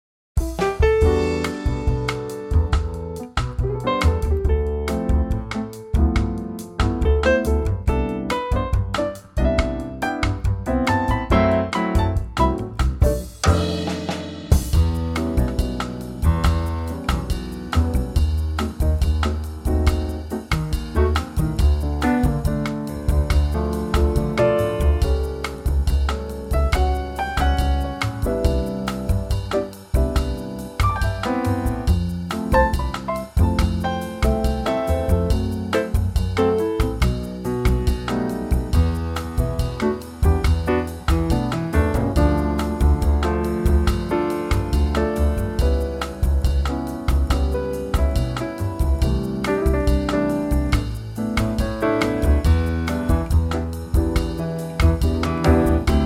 key Em
key - Em - vocal range - E to D
Wonderful Trio arrangement
slightly brighter in tempo.